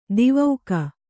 ♪ divauka